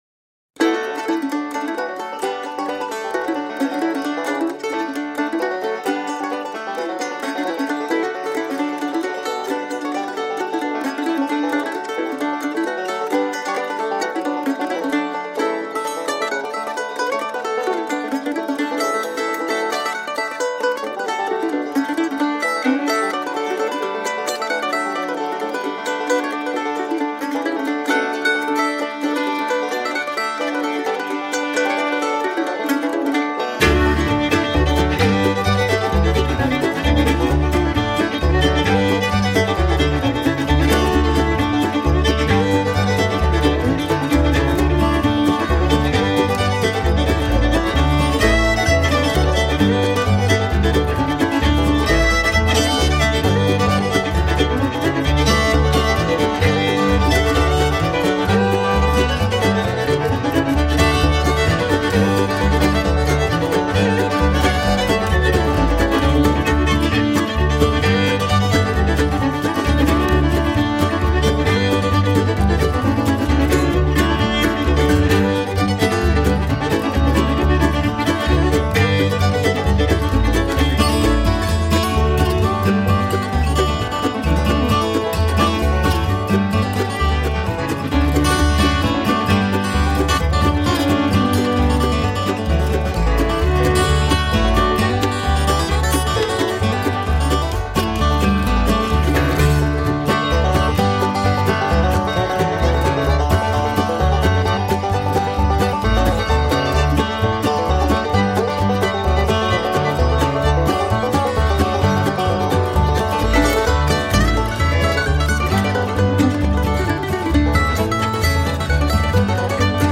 CUMBERLAND GAP | MANDOLIN
Cumberland Gap Traditional